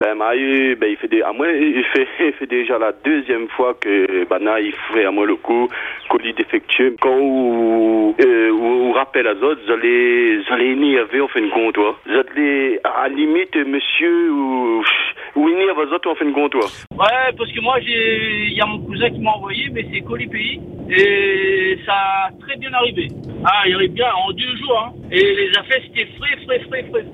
Messages, appels et commentaires se sont enchaînés autour des services de Chronopost, chacun partageant son expérience.